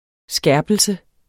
Udtale [ ˈsgæɐ̯bəlsə ]